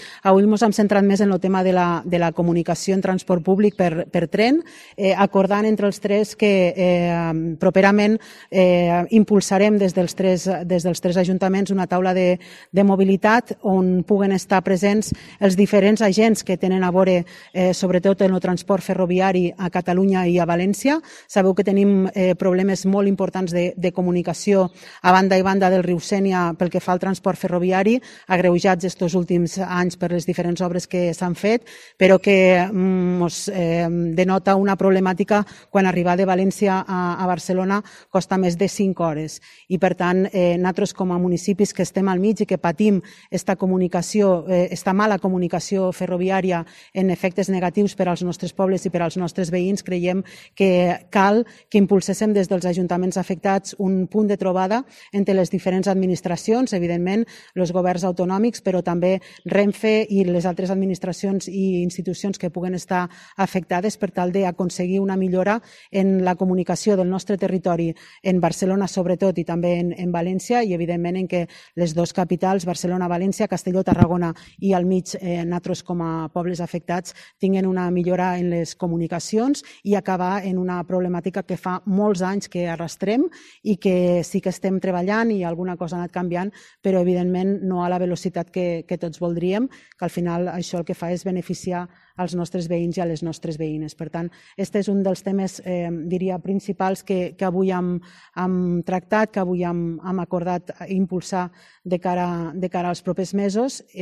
Nuria Ventura , alcaldessa d’Ulldecona
Guillem Alsina alcalde de Vinaròs
Joan Roig alcalde d’Alcanar